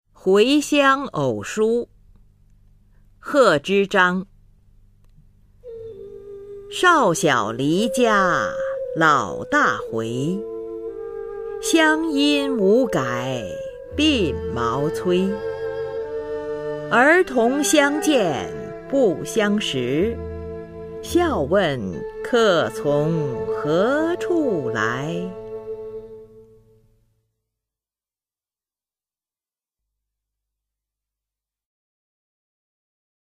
[隋唐诗词诵读]贺知章-回乡偶书 古诗文诵读